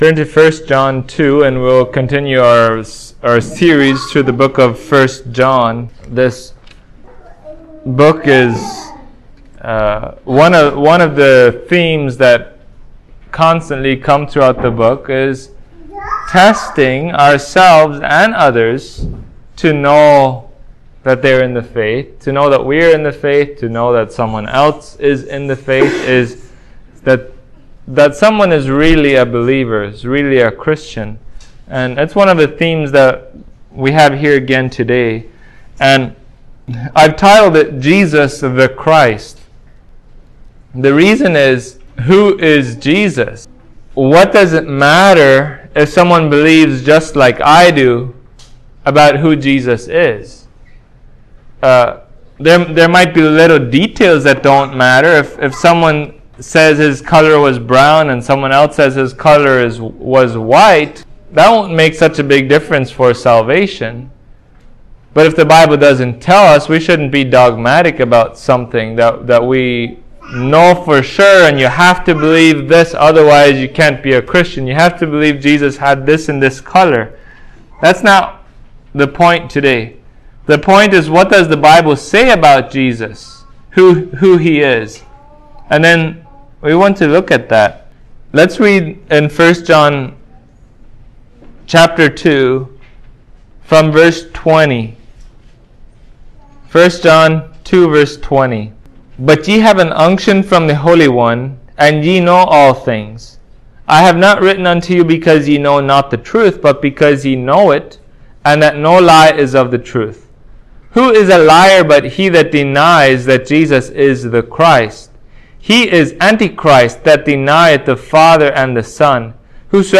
2023 Jesus the Christ Preacher